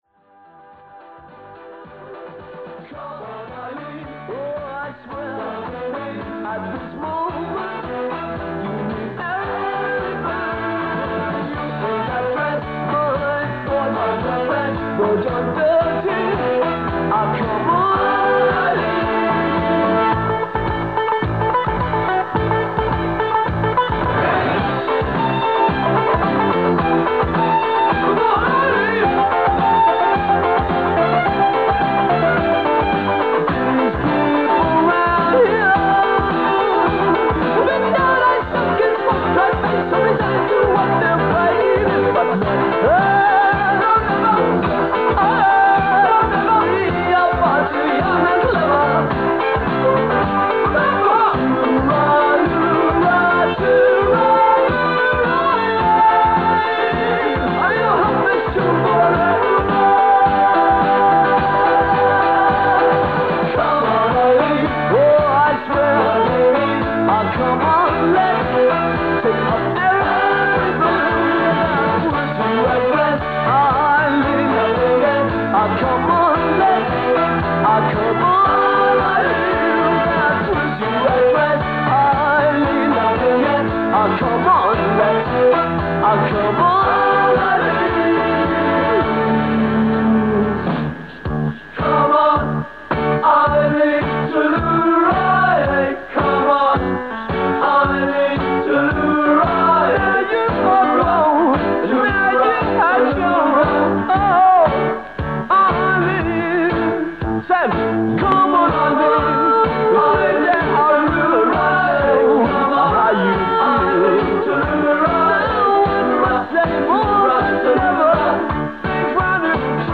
The Donegal pirate Radio North relaunched in 1999 with a new format of mostly American country music, calling itself ‘the new Radio North’.
As usual there are long commercial breaks featuring small businesses from Donegal, Antrim and Derry.